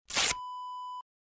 Paper Rip / Tear Wav Sound Effect #11
Description: The sound of a quick paper rip (light paper)
Properties: 48.000 kHz 16-bit Stereo
A beep sound is embedded in the audio preview file but it is not present in the high resolution downloadable wav file.
paper-rip-preview-11.mp3